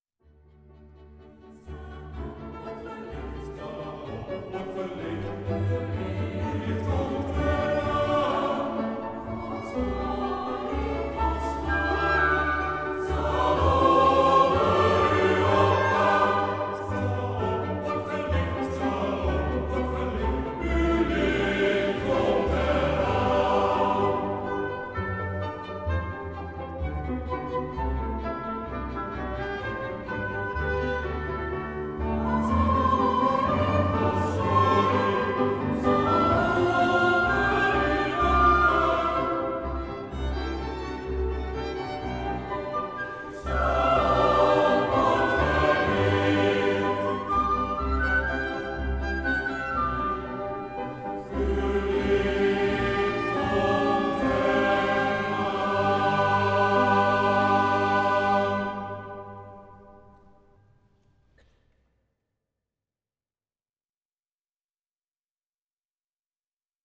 CANTATE